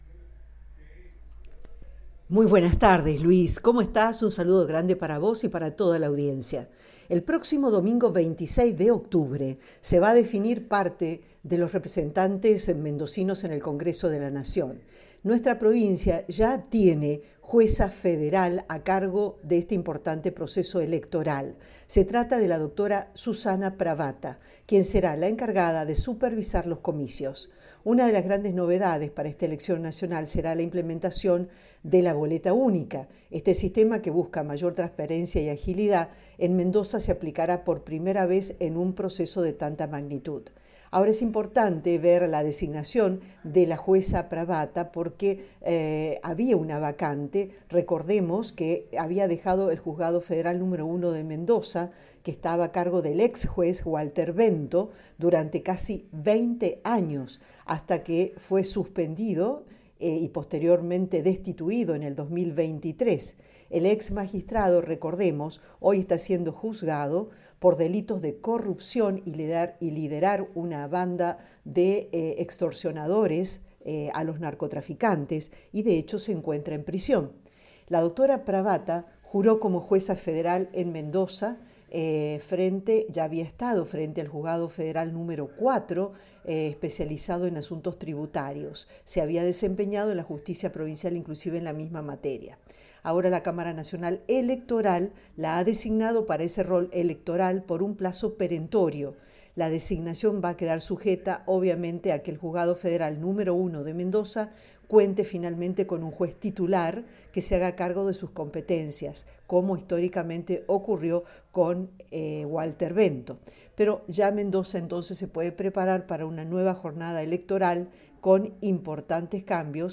Mendoza se prepara para comicios con boleta única y nueva jueza federal - Boletín informativo - Cadena 3 - Cadena 3 Argentina